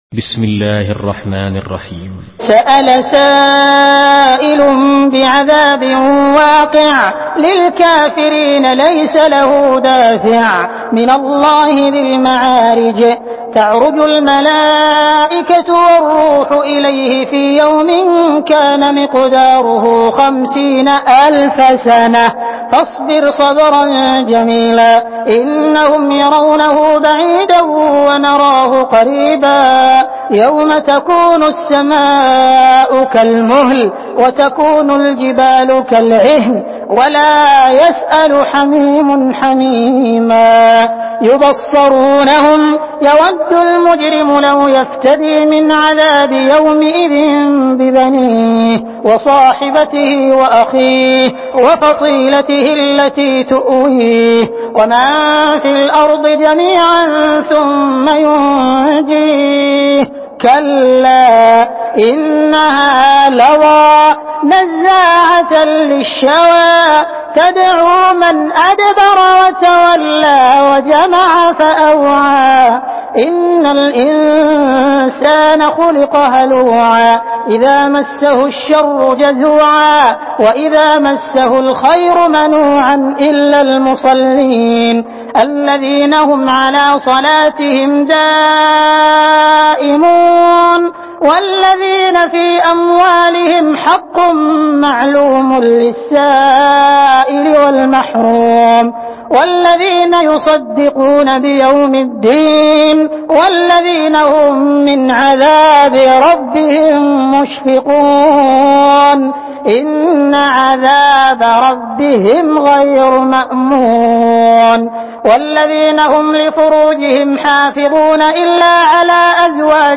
Surah Mearic MP3 in the Voice of Abdul Rahman Al Sudais in Hafs Narration
Surah Mearic MP3 by Abdul Rahman Al Sudais in Hafs An Asim narration.
Murattal Hafs An Asim